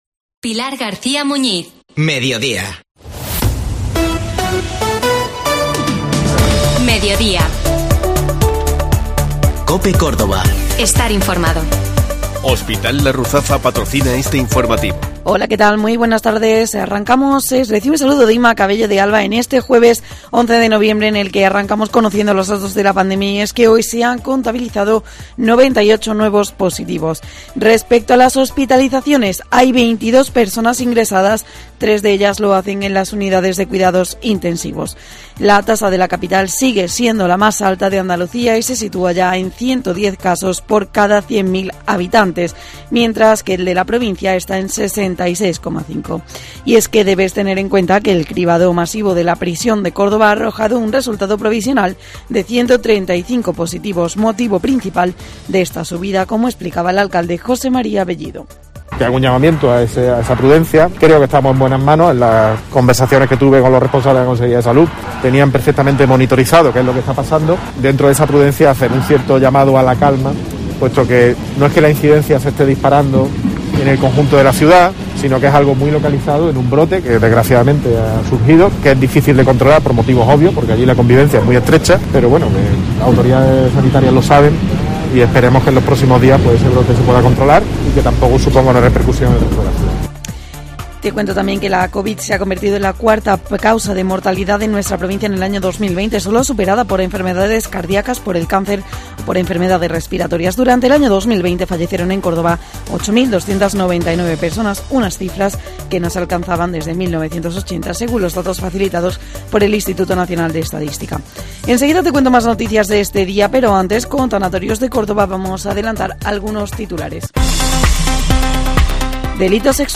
Un repaso diario a la actualidad y a los temas que te preocupan.